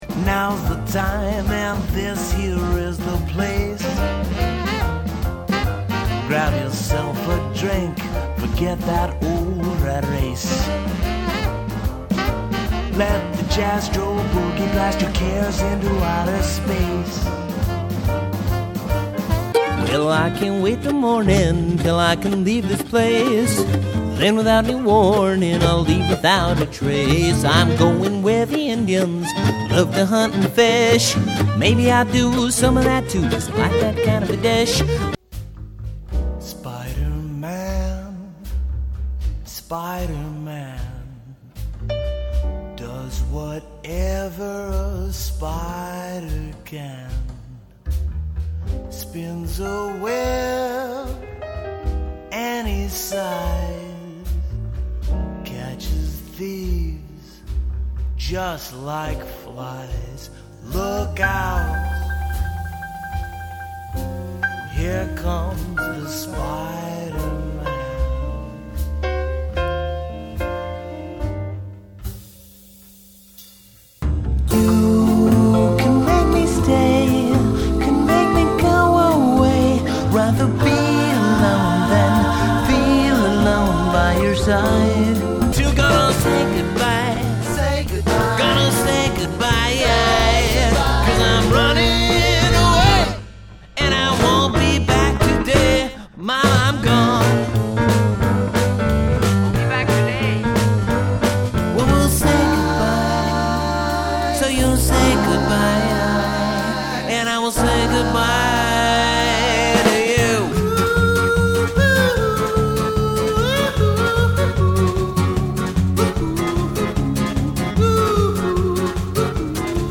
Singing Reel